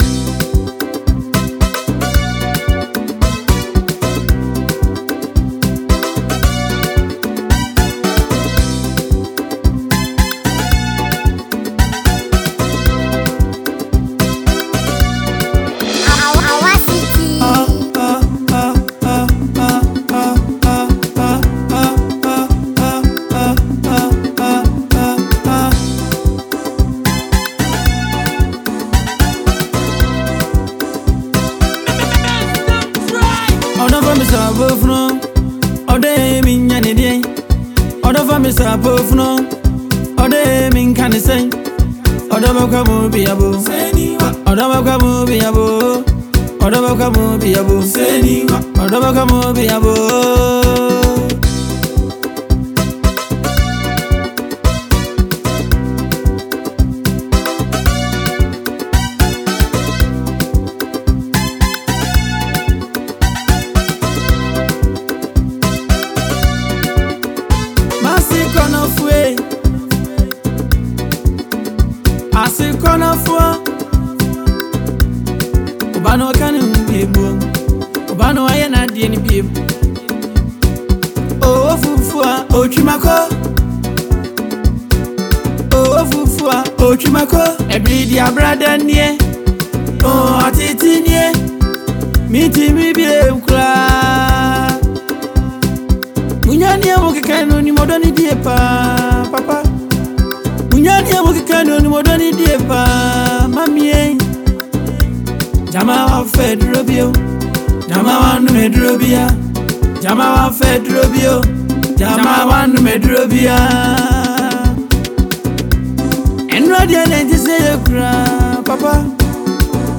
Ghanaian singer
highlife